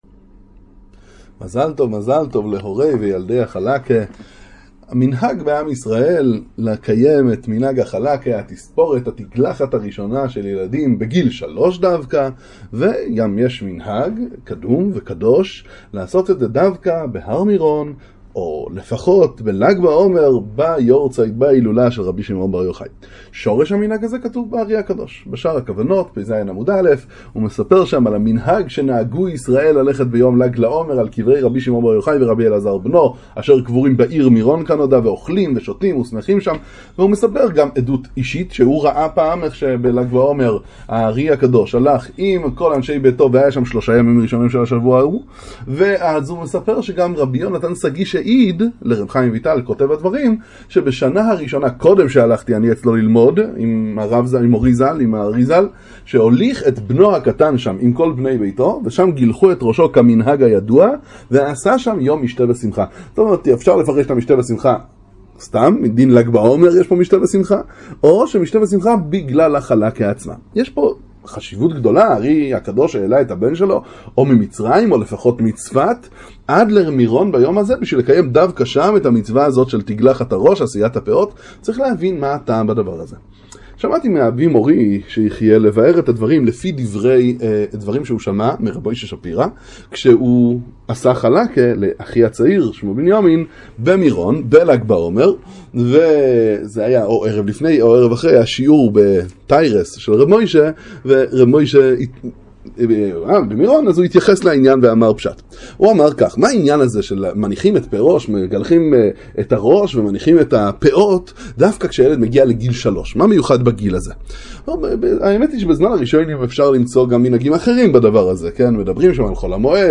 שיעור בתורת רבי שמעון בר יוחאי